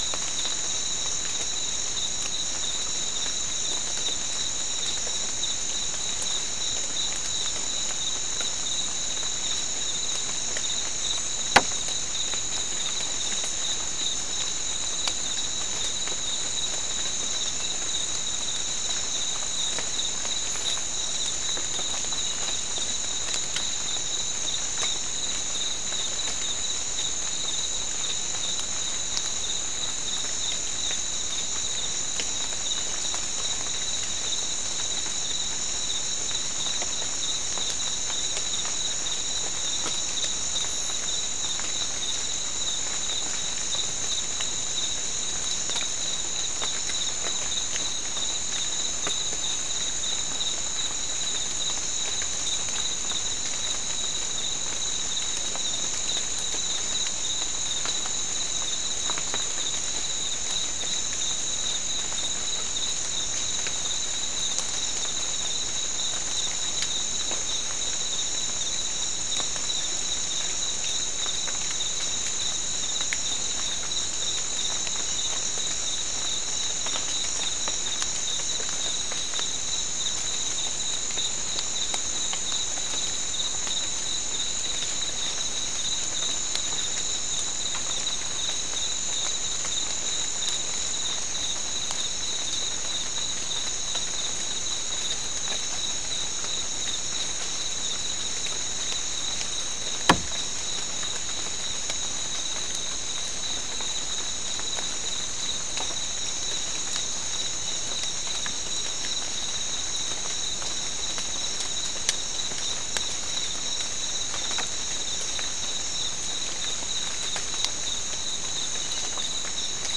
Soundscape Recording Location: South America: Guyana: Sandstone: 2
Recorder: SM3